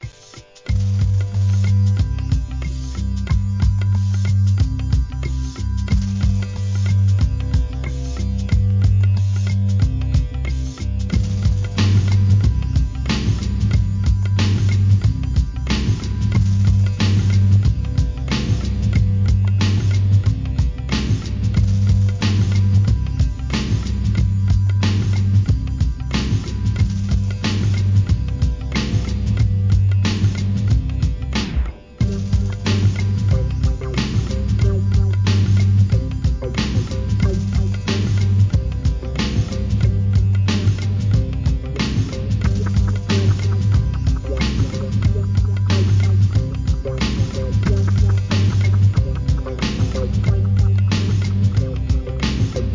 HIP HOP/R&B
ダビィーなブレイクビーツ仕上げのExtended Mixも使えます!!